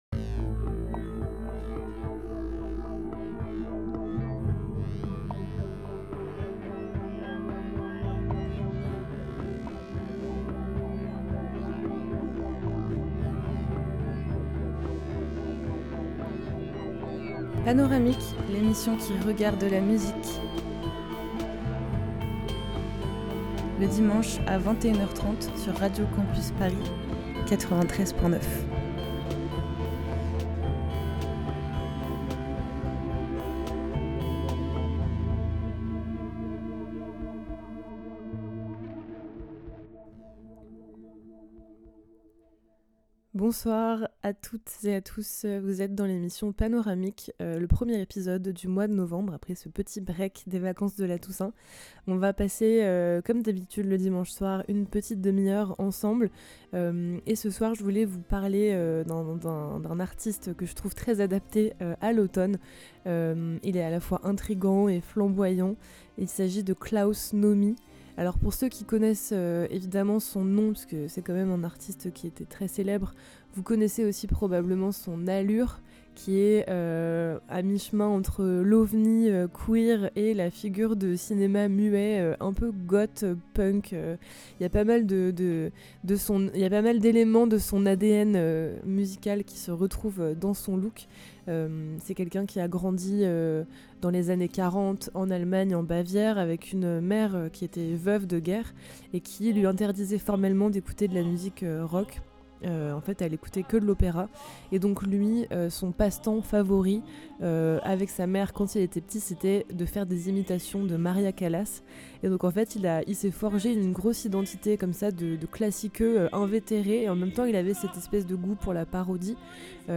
Mix
Éclectique